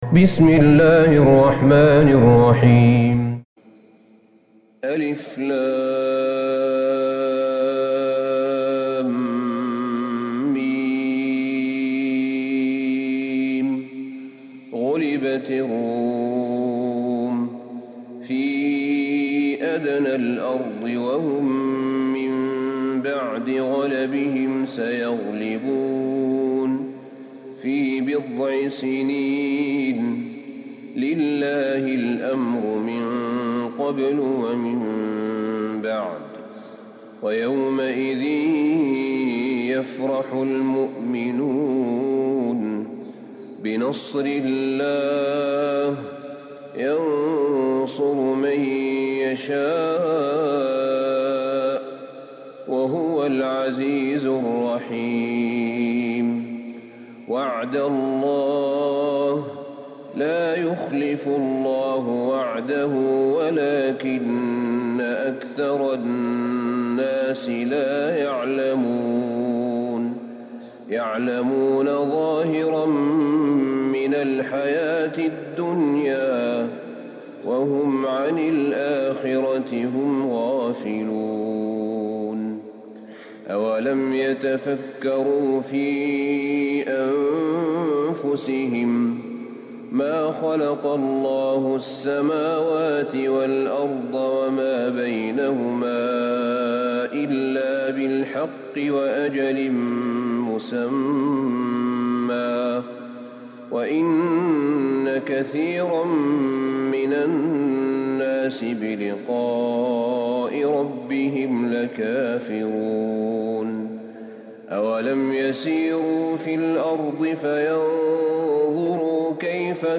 سورة الروم Surat ArRum > مصحف الشيخ أحمد بن طالب بن حميد من الحرم النبوي > المصحف - تلاوات الحرمين